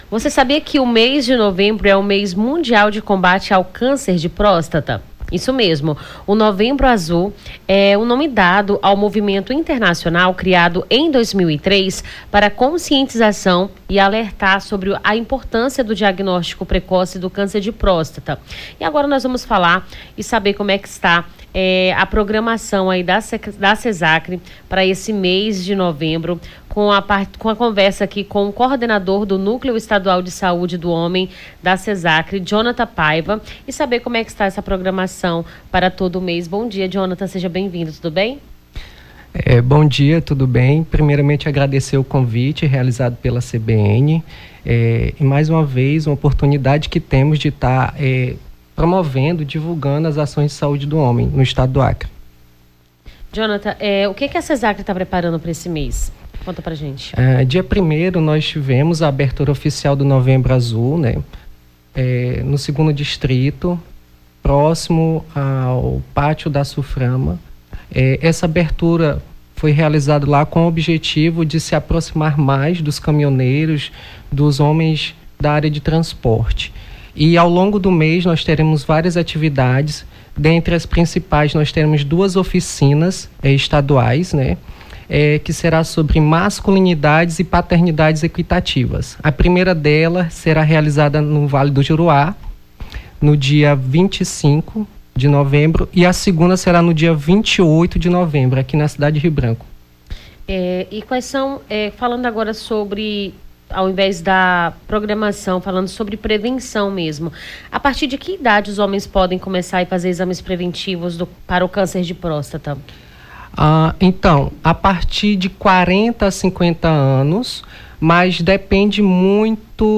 Nome do Artista - CENSURA- ENTREVISTA MOVEMBRO AZUL (04-11-24).mp3